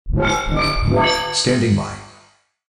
落ち着いた男性の声で「Standing by」と伝える、シンプルで洗練された充電音と通知音です。